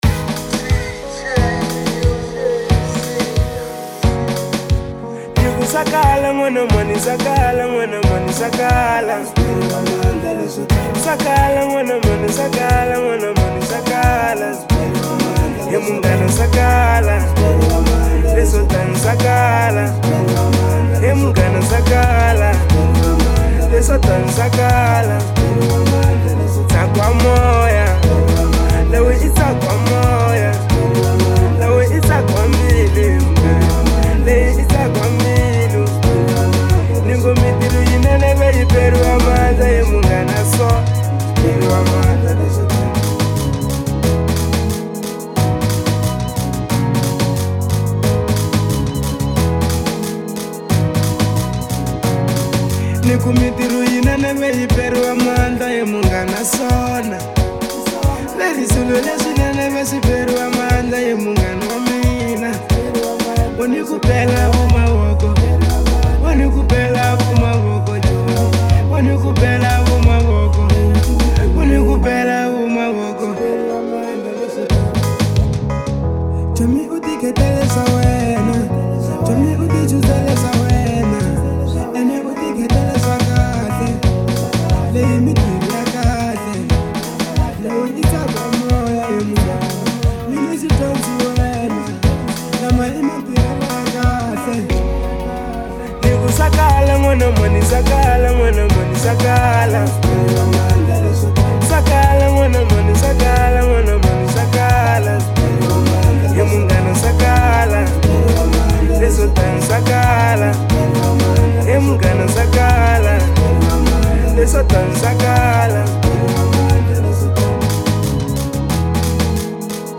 03:26 Genre : Marrabenta Size